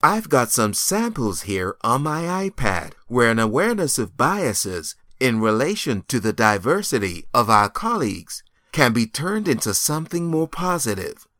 EN US KD EL 01 eLearning/Training Male English (American)